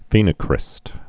(fēnə-krĭst)